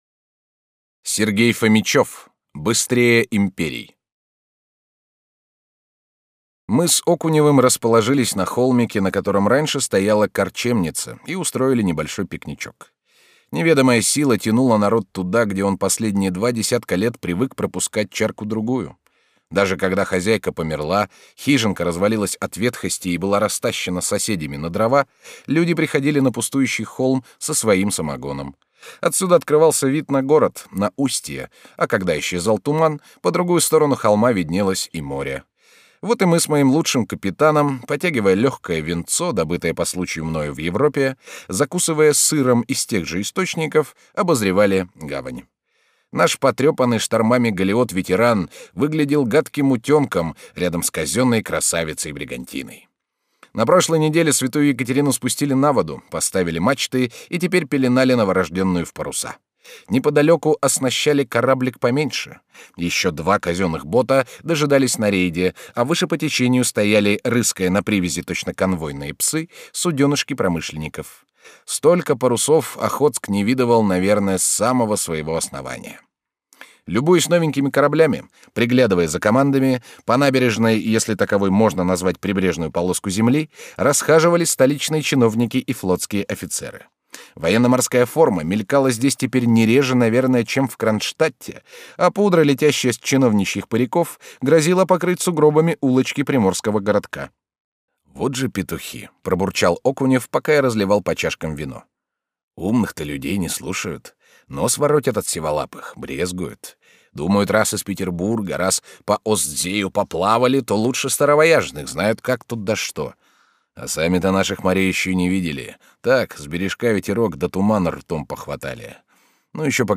Аудиокнига Быстрее империй | Библиотека аудиокниг